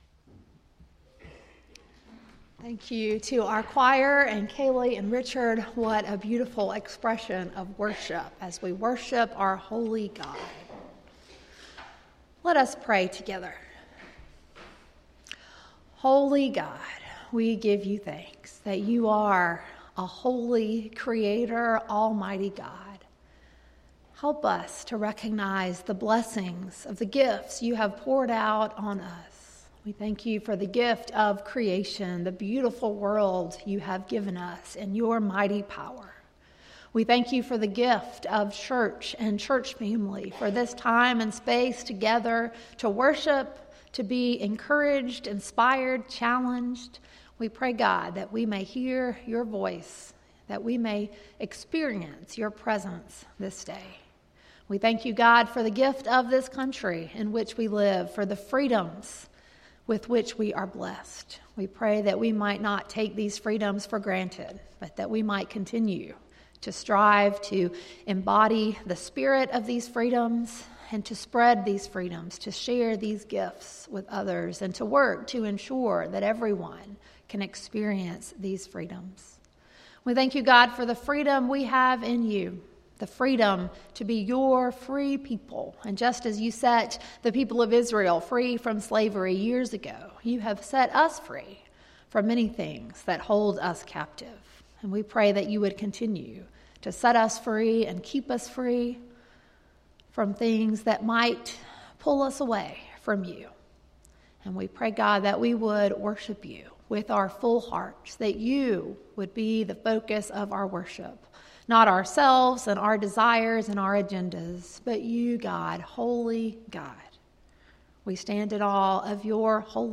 Sermon, Worship Guide, and Announcements for July 7, 2019 - First Baptist Church of Pendleton